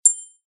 На этой странице собраны звуки хрусталя: легкие перезвоны, тонкие вибрации и магические мелодии.
Звук хрустального деликатного пинка